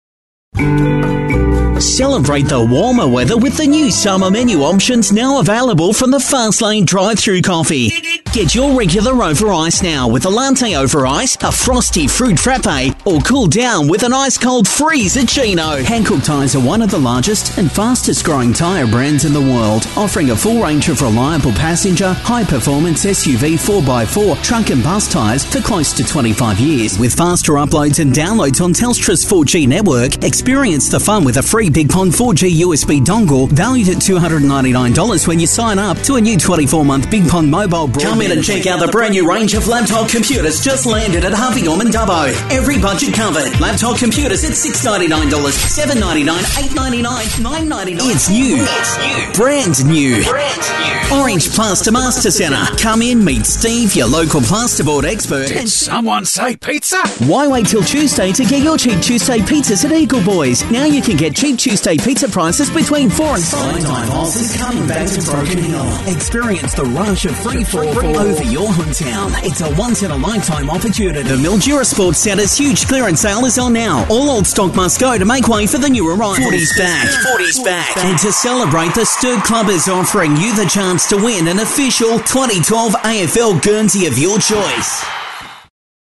Male
English (Australian)
Hard Sell, Promotions, Upbeat, Grown up-mature, Caring-concerned, Energy-excitement, Friendly-sincere, Perky-upbeat, Soft-easy-mellow, Fresh, Vibrant, Typical announcer-speaker, Warm-intimate.
The boy next door giving a smooth delivery with a crisp, clean sound.
Australian, laid back, youthful, warm, surfy, guy.
Radio Commercials